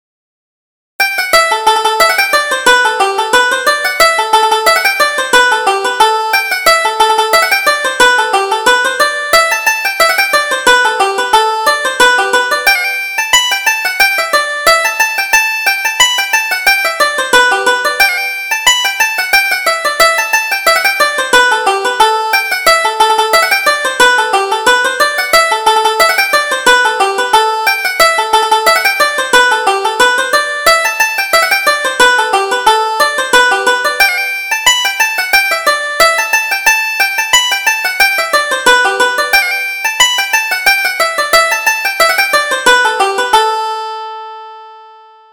Reel: On the Sly